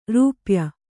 ♪ rūpya